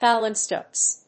音節pal・imp・sest 発音記号・読み方
/pˈælɪm(p)sèst(米国英語), ˈpælɪˌsest(英国英語)/